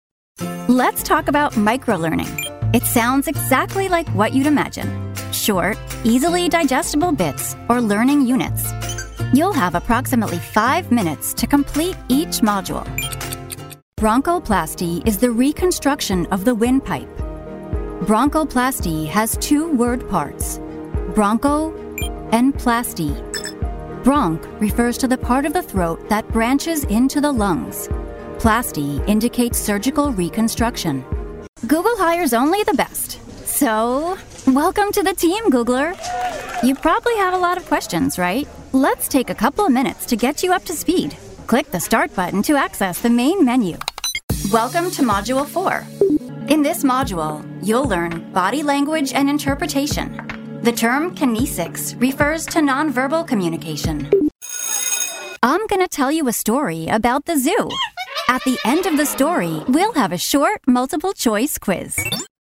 Female
Bright, Bubbly, Friendly, Warm, Confident, Natural, Young, Approachable, Conversational, Energetic, Soft, Upbeat
Microphone: TLM 102, RE20
Audio equipment: Whisper Room Booth, Apollo Twin Interface, DBX 286s preamp/processor, Aventone speakers